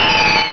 pokeemmo / sound / direct_sound_samples / cries / altaria.wav